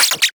scratch_005.ogg